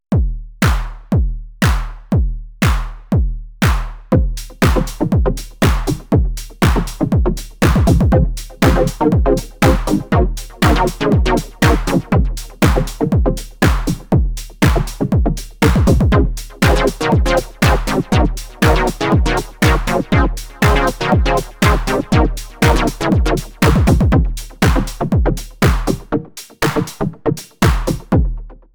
Worked on a 909ish clap
It sounds fantastic!